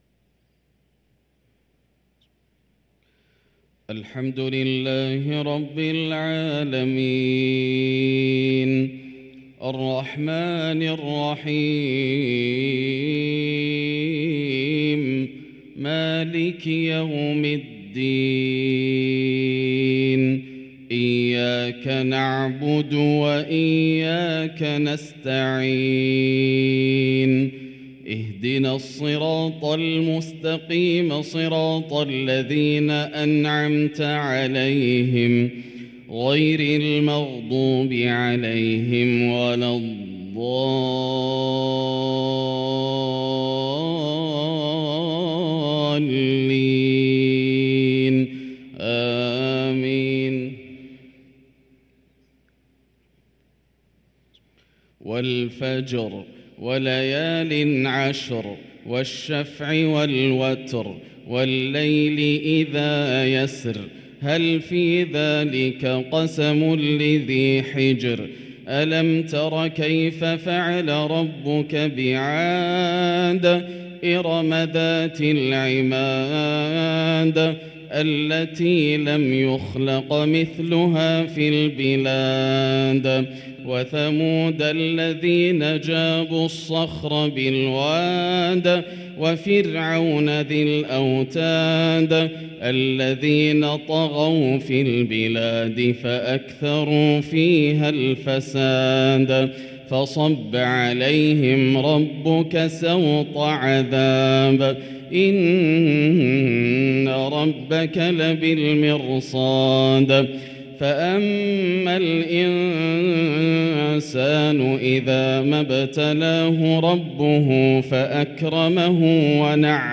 صلاة العشاء للقارئ ياسر الدوسري 9 شعبان 1444 هـ